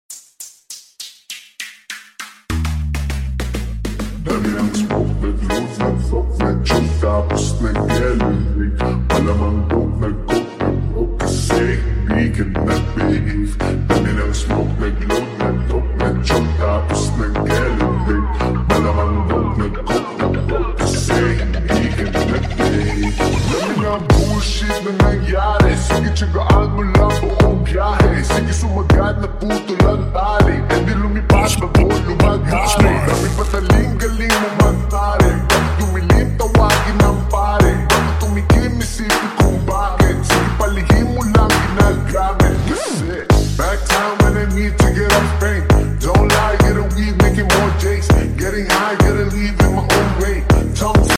SLOWED FULL BASS REMIX